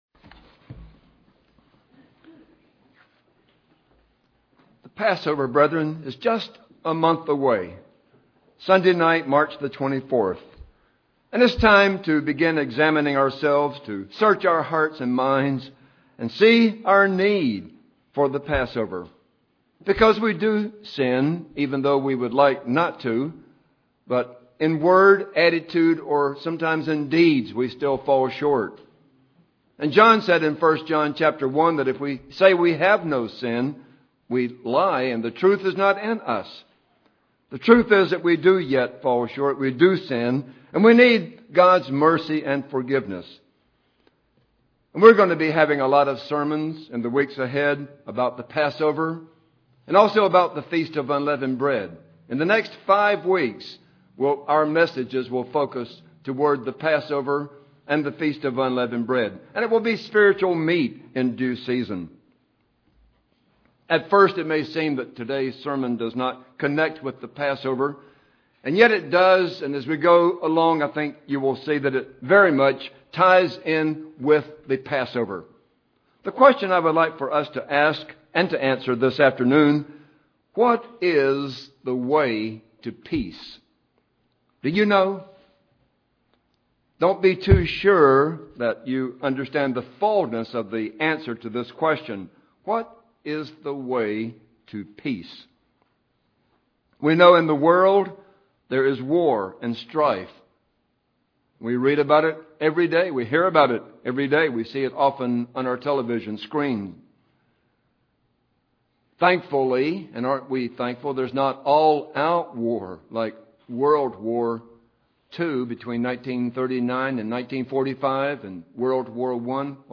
You can have peace of mind today. Listen to this sermon to learn how.